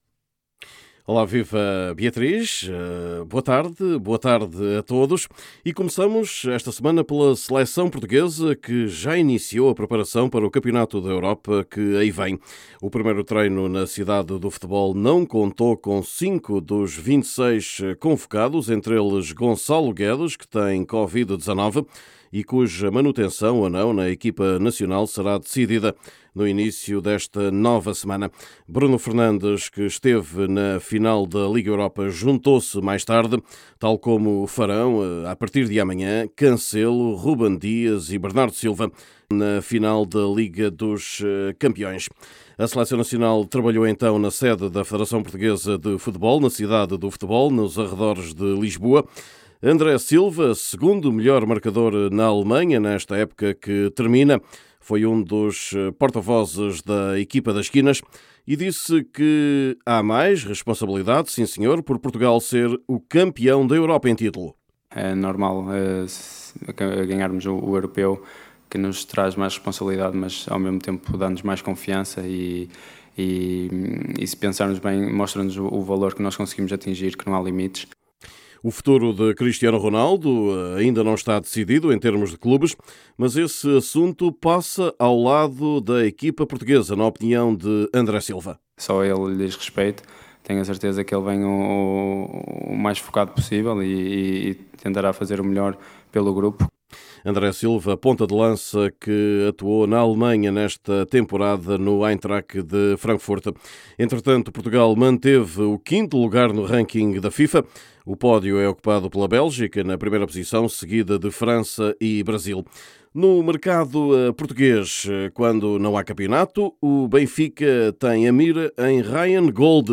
Neste boletim